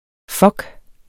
Udtale [ ˈfʌg ]